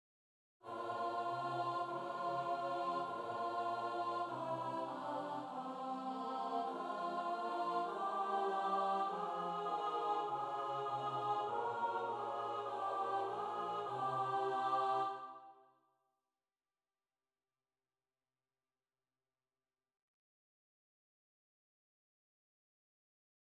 요한 제바스티안 바흐의 ''마태 수난곡'' 제19곡에서 겟세마네 동산에서 그리스도의 고뇌를 전달하는 부분에는, 합창단의 코랄 화성 중 "Plagen"(고통)이라는 단어에 나폴리 화음이 사용된다.
존 엘리엇 가디너는 "대답하는 부드러운 목소리의 합창단은... [음악]에 신비로운 분위기를 불어넣어 마치 주요 행동에서 멀리 떨어진 곳에서 조용한 드라마가 펼쳐지는 듯한 느낌을 준다 - 그리스도의 '겟세마네 동산에서의 고뇌'와 구세주로서의 그의 역할을 받아들임."이라고 설명한다.[12]